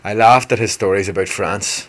Examples of Northern Ireland English
//ɑɪ lɑːft ət hɪz ʹstɔːriz ʹəbəʊt fræns//
The pronunciation of France is more similar to American English than RP.